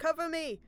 Combat Dialogue
Becca Cover me.wav